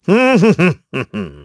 KaselB-Vox_Hum_kr_b.wav